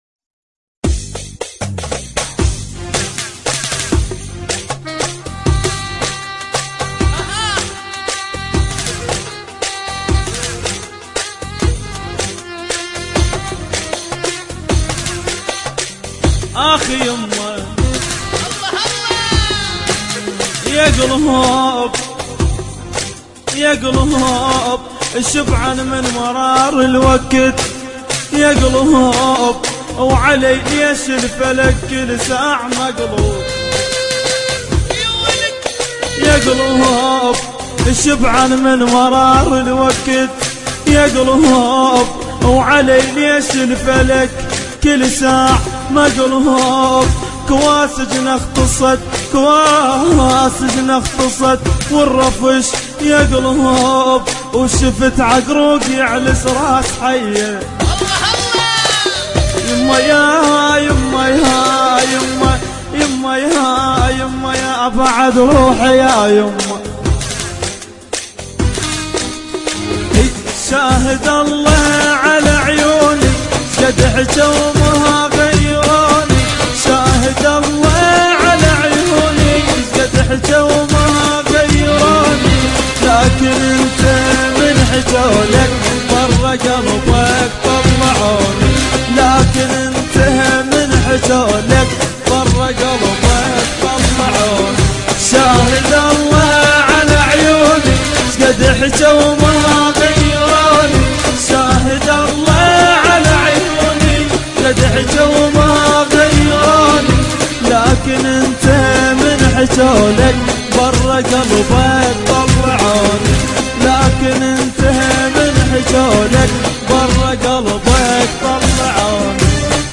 آهنگ عربی